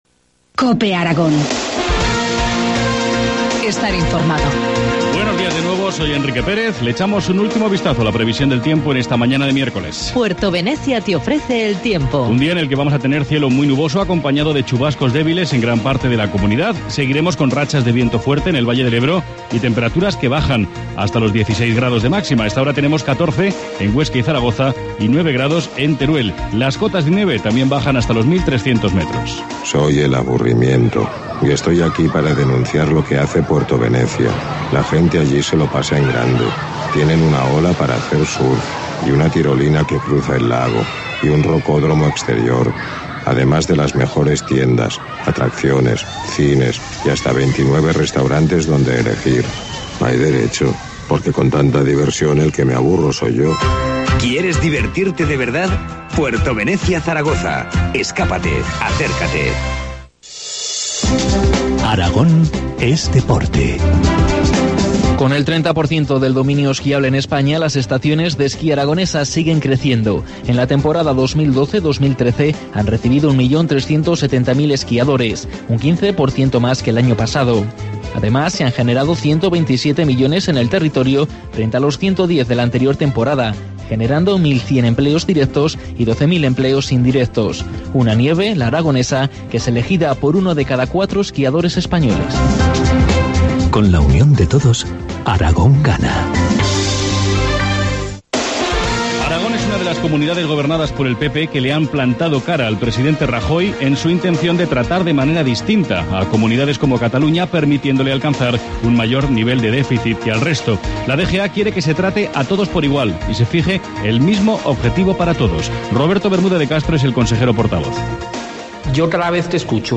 Informativo matinal, miércoles 15 de mayo, 8.25 horas